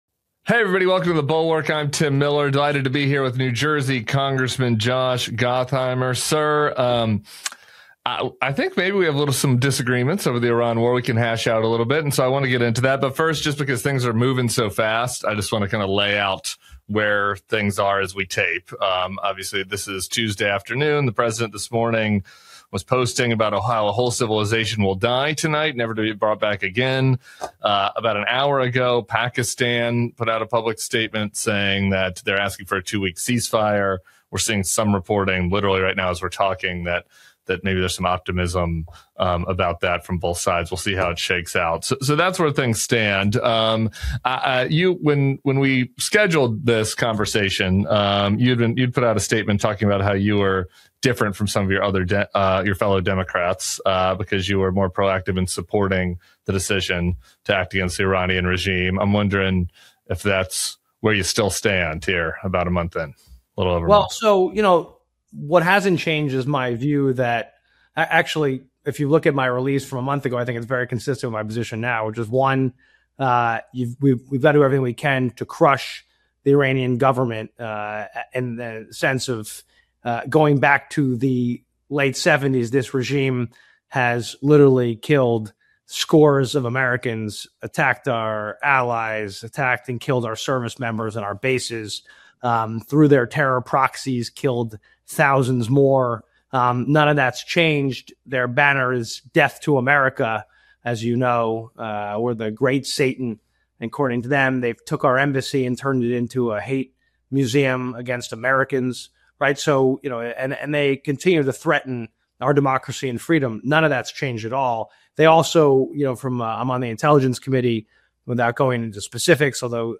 Rep. Josh Gottheimer joins Tim Miller for a heated debate over the war with Iran—where even pro-war lawmakers admit they still don’t know the plan. They clash over whether weakening Iran justifies the risks, rising costs at home, and the lack of clear objectives, while digging into Trump’s leadership, Israel’s role, and the looming War Powers showdown in Congress.